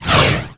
staffswing_2.wav